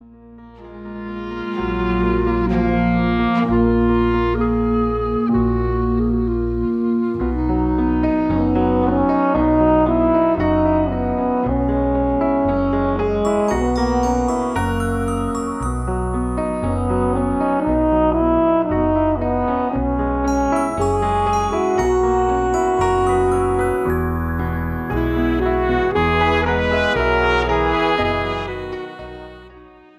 A Musical Download Album for $11.99
cast recording